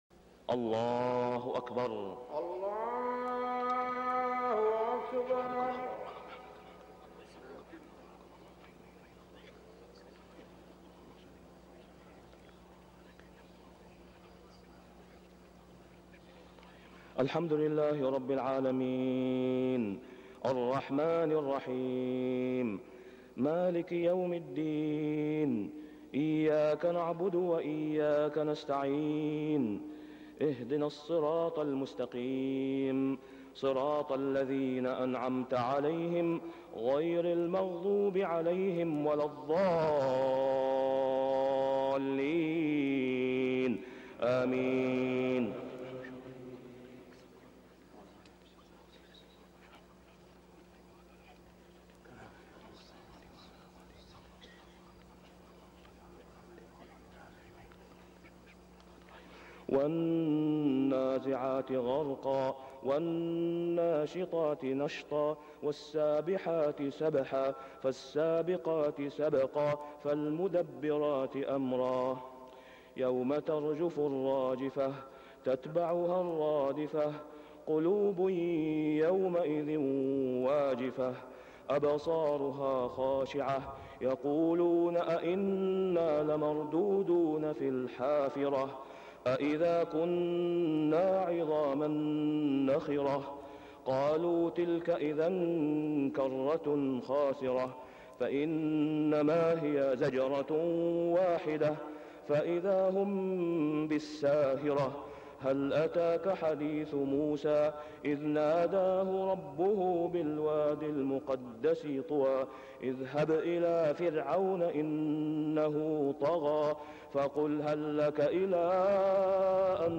صلاة الفجر ذوالقعدة 1421هـ سورتي النازعات و عبس > 1421 🕋 > الفروض - تلاوات الحرمين